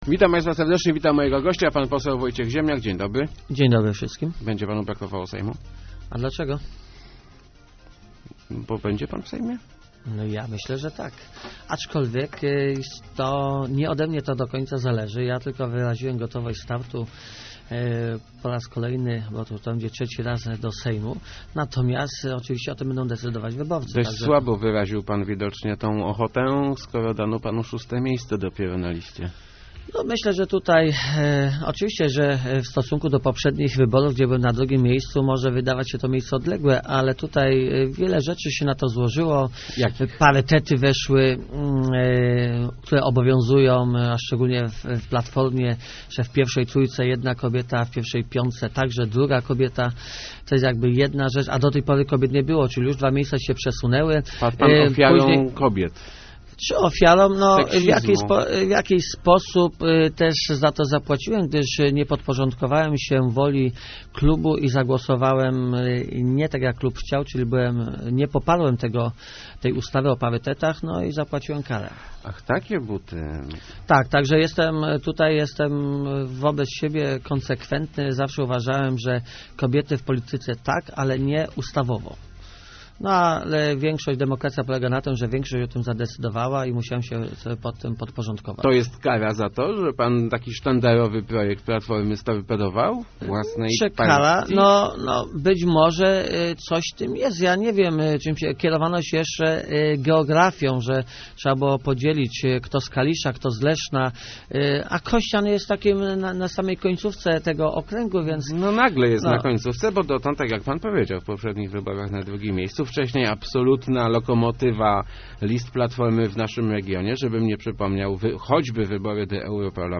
Zap�aci�em kar� za to, �e nie popar�em parytetów - mówi� w Rozmowach Elki pose� Wojciech Ziemniak, komentuj�c swoje szóste miejsce na li�cie wyborczej PO.